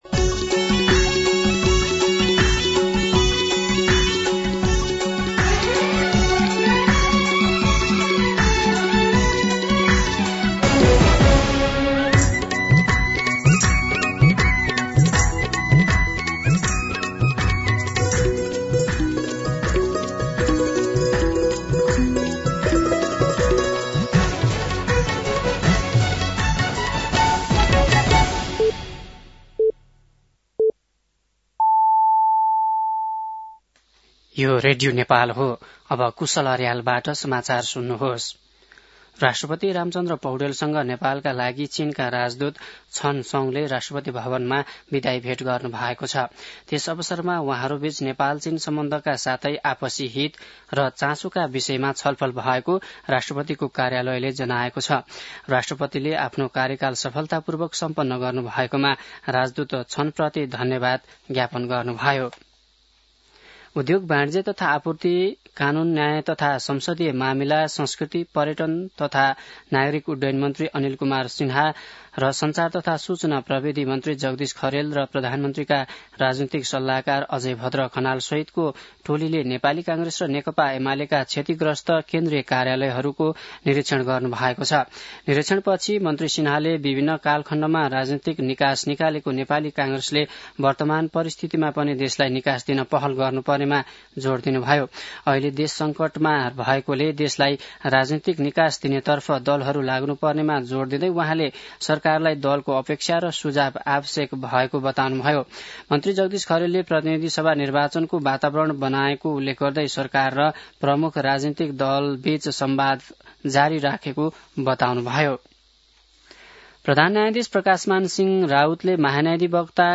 दिउँसो ४ बजेको नेपाली समाचार : ८ पुष , २०८२
4pm-News-09-8.mp3